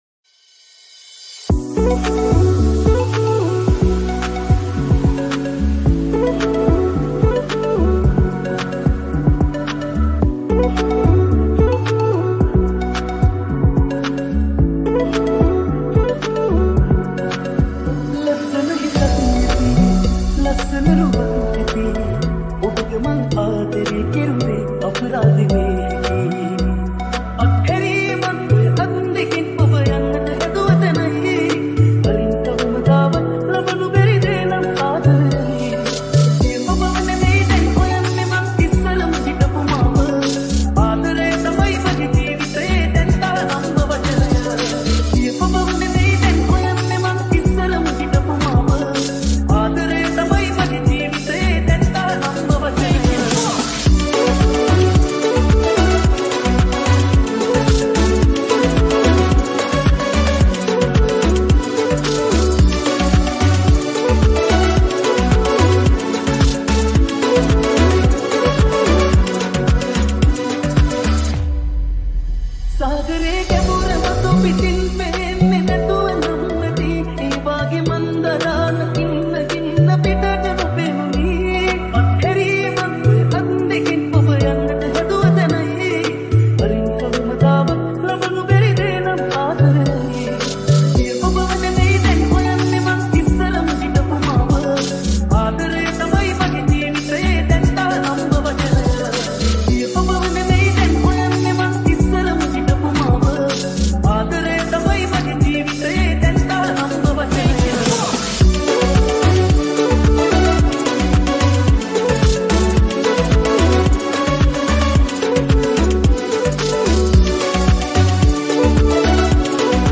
Sinhala Remix Songs || Sinhala DJ Song